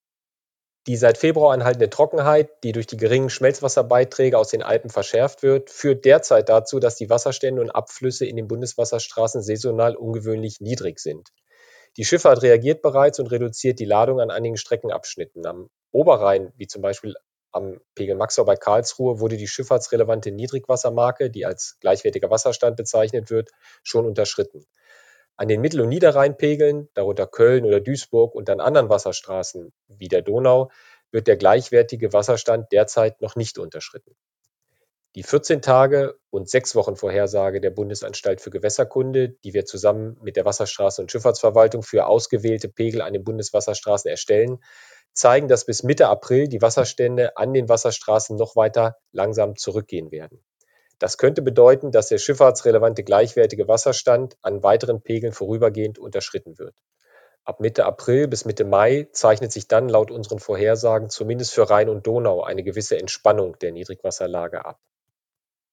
Anhang: O-Töne zur aktuellen Niedrigwasserlage an den Bundeswasserstraßen